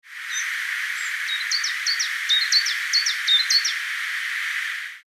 vrijwel helemaal fitis is, soms lijkt het